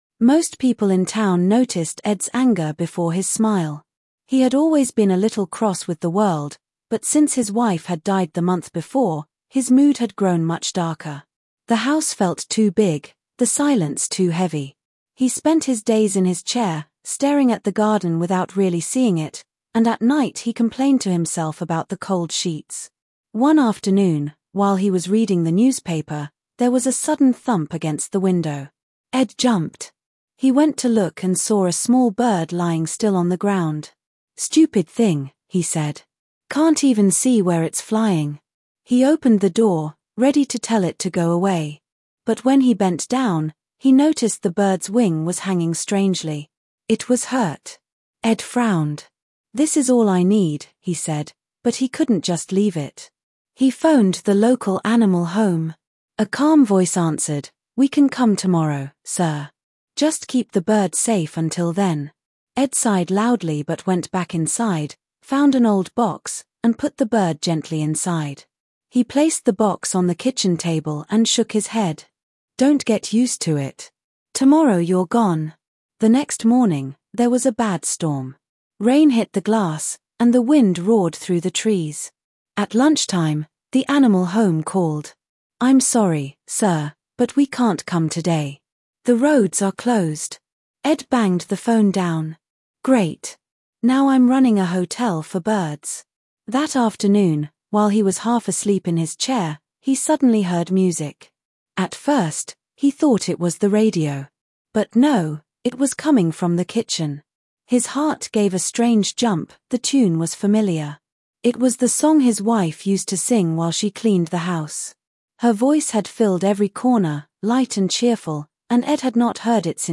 Każda historia z tej kolekcji nie jest przeznaczona tylko do czytania – jest również czytana na głos!
Each story in this collection isn’t just for reading—it’s also read aloud for you!